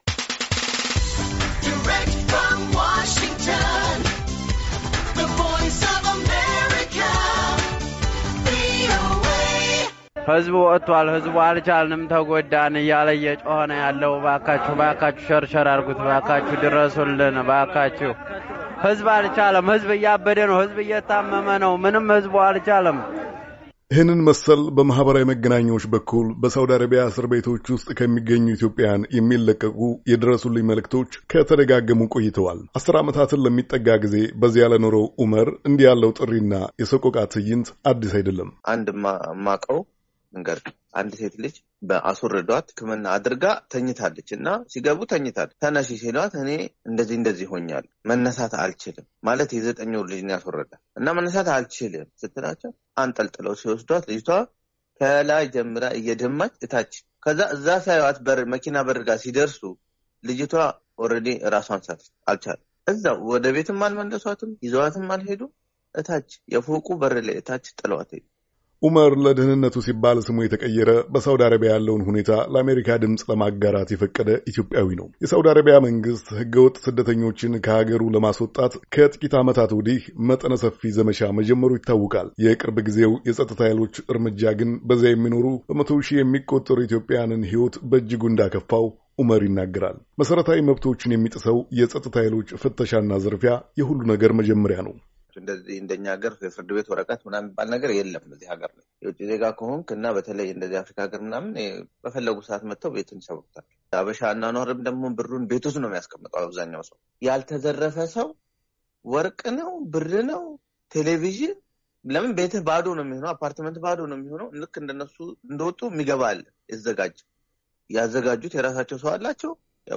በሺዎች የሚቆጠሩ ኢትዮጵያዊያን ስደተኞች በሳውዲ አረቢያ እስር ቤቶች ውስጥ ኢሰብዓዊ በሆነ አያያዝ ምክንያት እየተሰቃዩ እንደሆኑ በዚያ የሚኖሩ ኢትዮጵያዊያን እና የለውጥ አራማጆች ለአሜሪካ ድምጽ ተናግረዋል። የኢትዮጵያዊያኑን ህይወት ለማትረፍ እና ወደ ሀገራቸው እንዲመለሱ ለማድረግ ጥረት ማድረግ እንደሚገባም አሳስበዋል ።